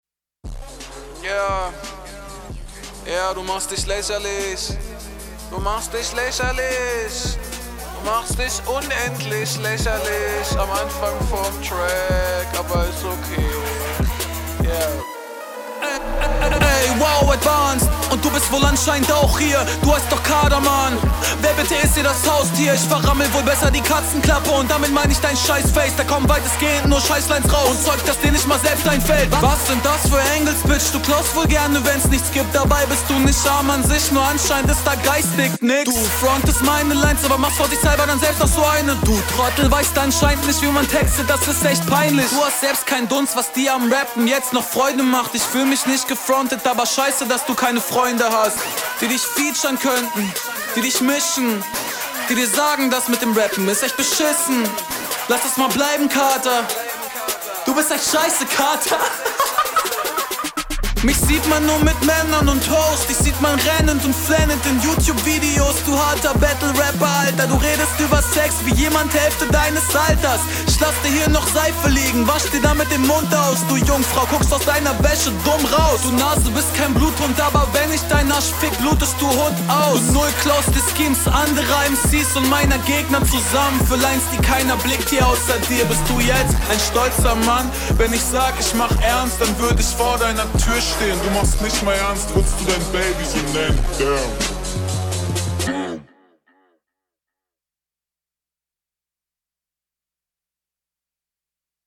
Bist viel besser auf dem Beat - taktischer …